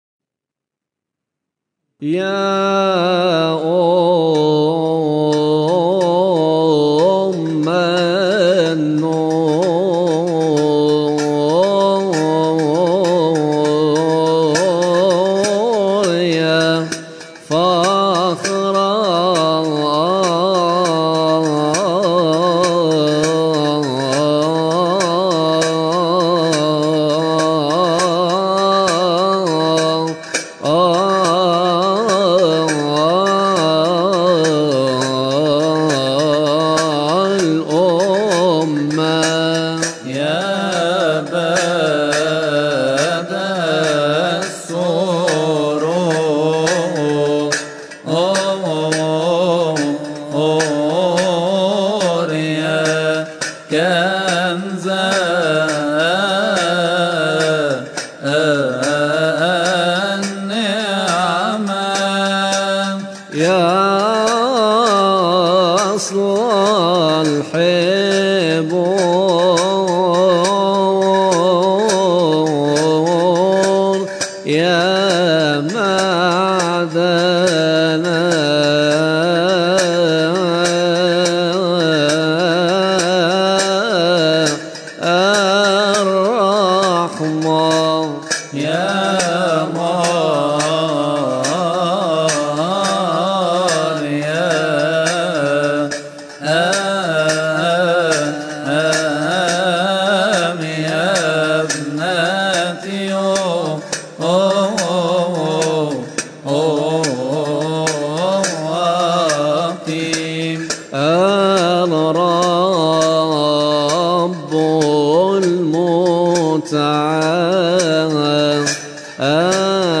استماع وتحميل لحن مديحة يا أم النور يا فخر الأمة من مناسبة nhdet-al3dra